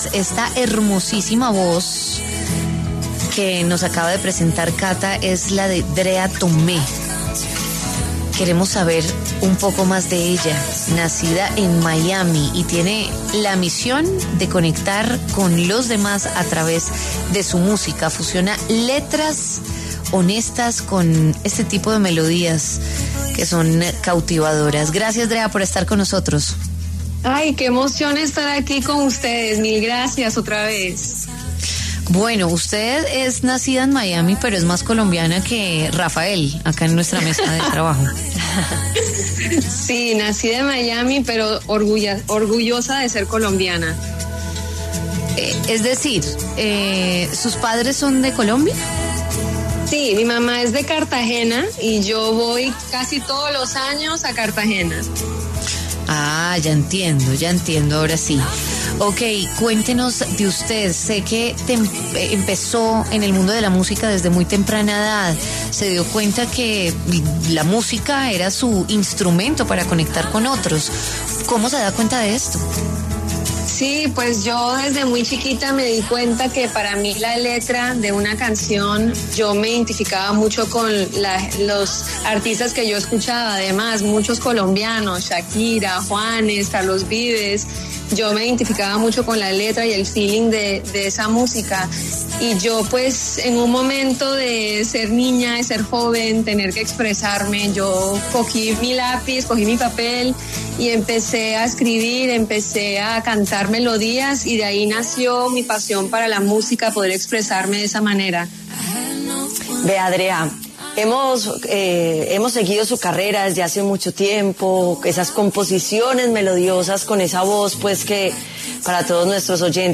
cantautora